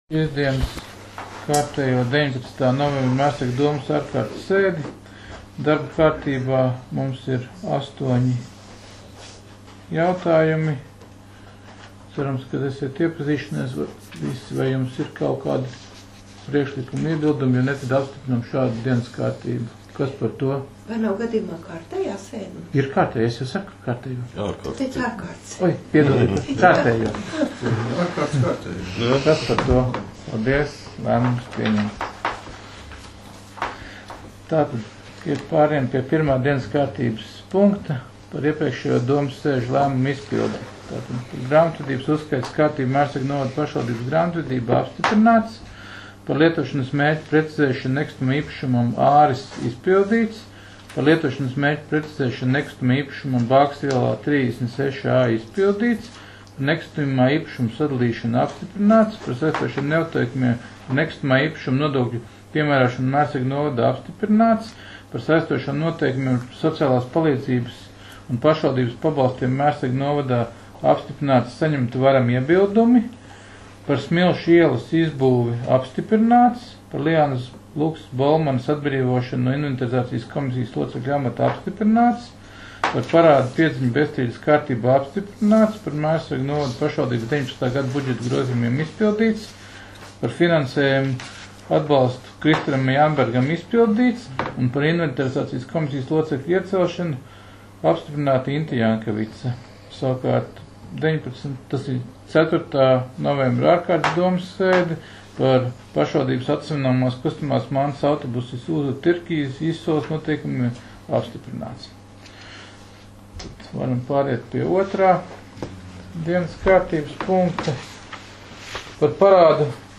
Mērsraga novada domes sēde 19.11.2019.